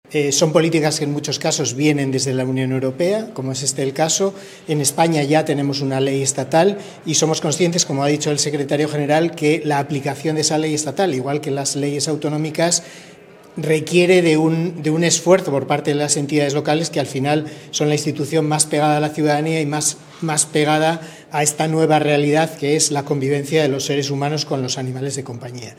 José Ramón Becerra, director general de Derechos de los Animales del Ministerio de Derechos Sociales, Consumo y Agenda 2030, ha recordado el convenio firmado con la FEMP para dar formación a todos los ayuntamientos en materia de bienestar animal, “es decir para poder actuar en estas políticas públicas”.